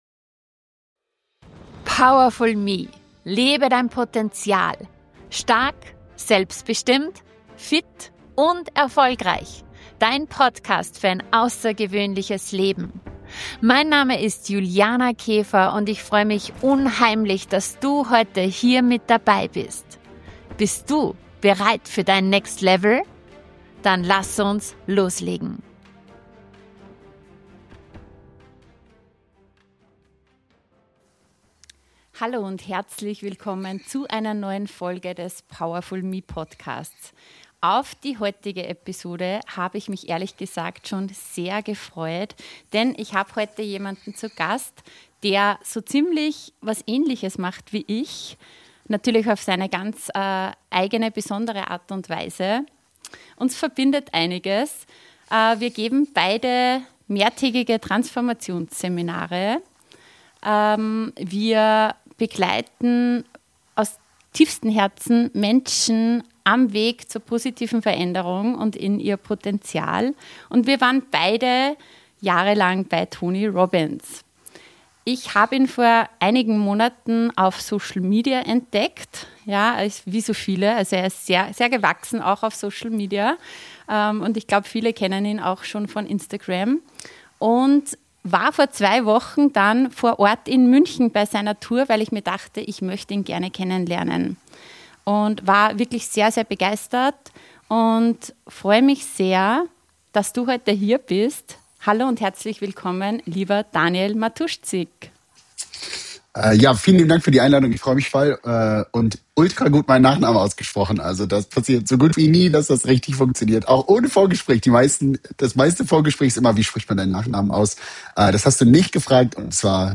Ein ehrliches Gespräch über Wachstum, Freiheit und die Entscheidung, dein Leben nicht nur gut aussehen zu lassen – sondern nach deinen Werten zu leben.